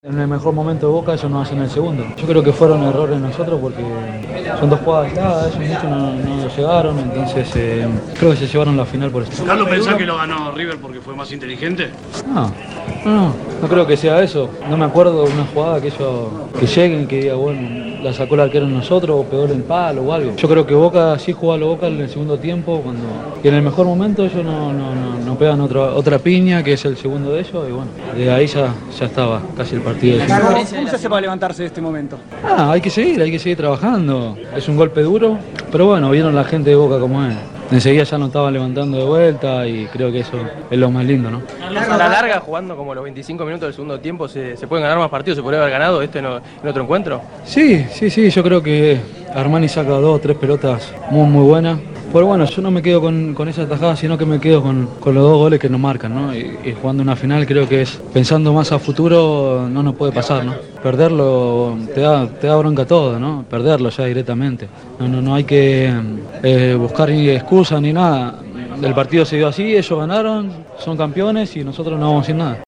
Carlos Tévez se mostró dolido luego de la derrota.
Audio de Carlos Tévez por RADIO EME: